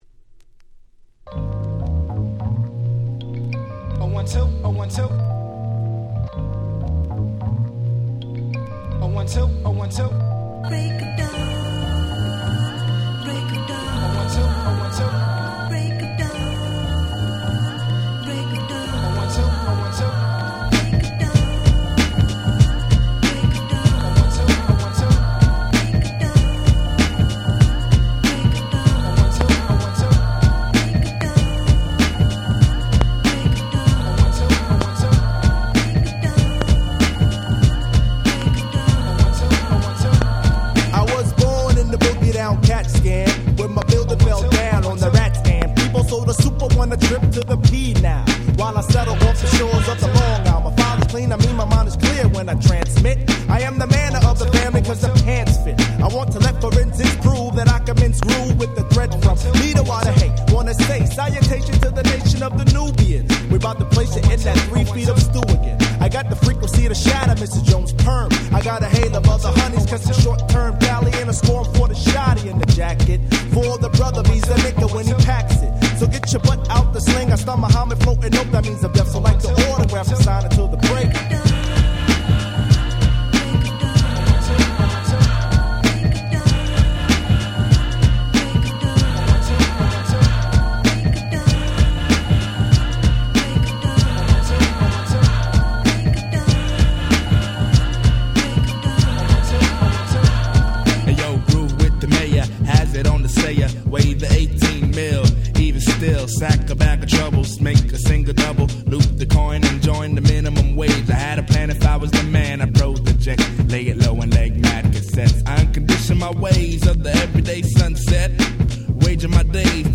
【Media】Vinyl 12'' Single
93' Super Hit Hip Hop !!
MellowでGroovyな問答無用のニュースクールクラシック！！